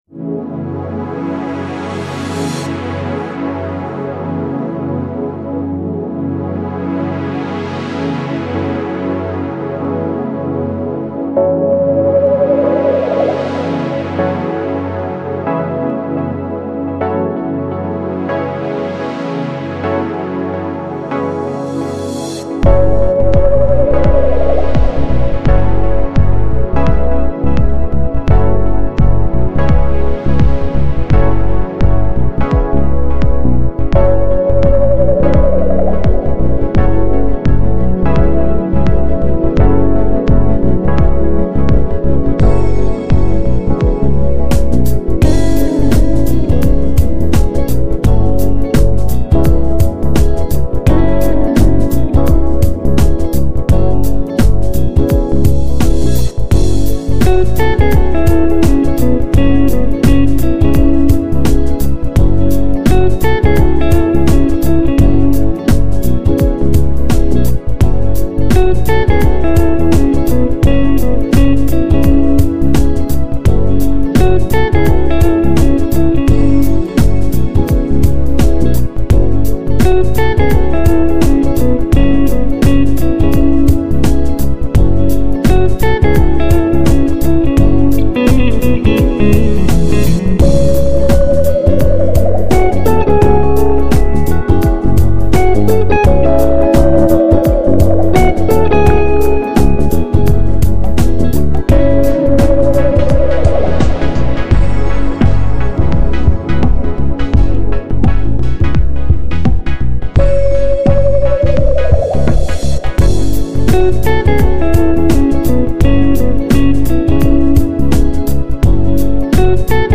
Lounge音乐的软调特性
没有激动的情绪，只有愉悦的笑声